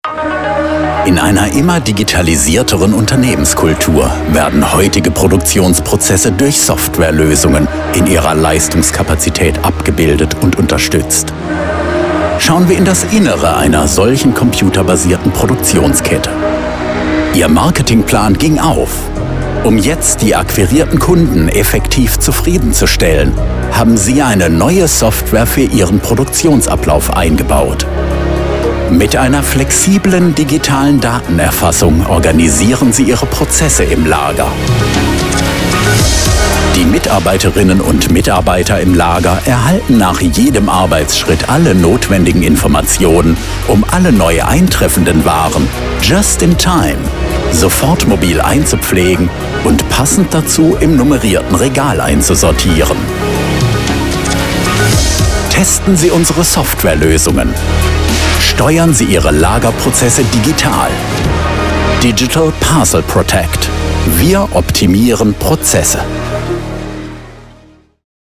Deutscher Sprecher, Bariton, Stimme für Trickfilm, Cartoon, Animation, Videogame, Trailer, Werbung
Kein Dialekt
Sprechprobe: Industrie (Muttersprache):